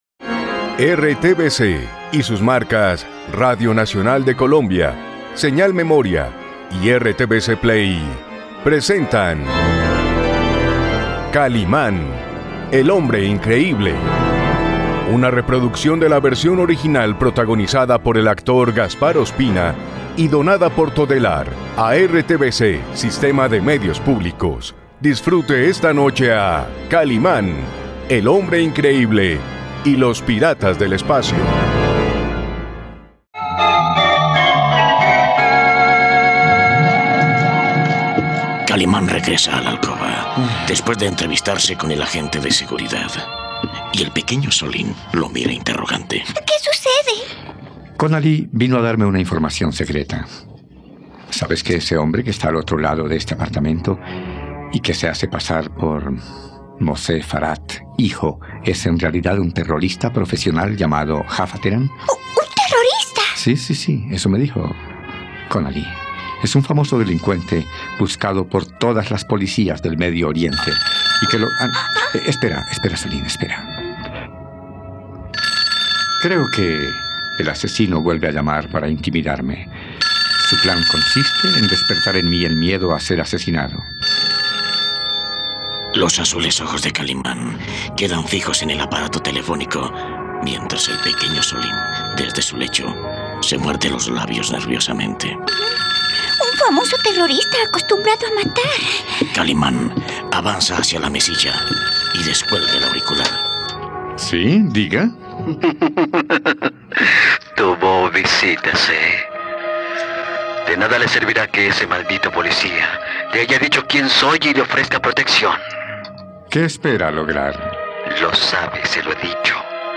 ..Sacha observa al hombre increíble y le confiesa su atracción, ¿qué sucederá ahora? No te pierdas la radionovela de Kalimán y los piratas del espacio aquí, en RTVCPlay.